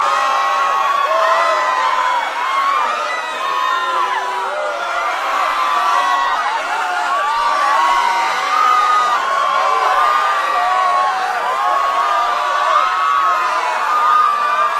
Звуки кричащей толпы